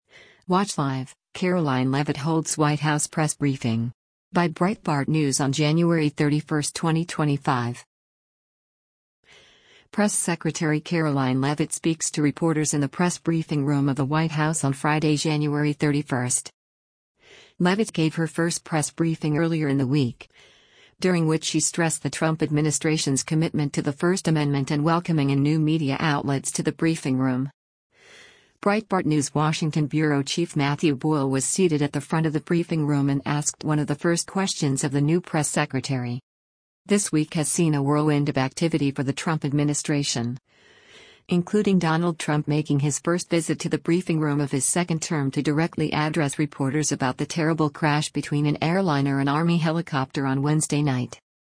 Press Secretary Karoline Leavitt speaks to reporters in the Press Briefing Room of the White House on Friday, January 31.